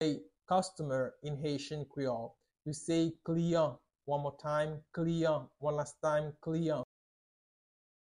Listen to and watch “Kliyan” audio pronunciation in Haitian Creole by a native Haitian  in the video below:
7.How-to-say-Customer-in-Haitian-Creole-–-Kliyan-pronunciation.mp3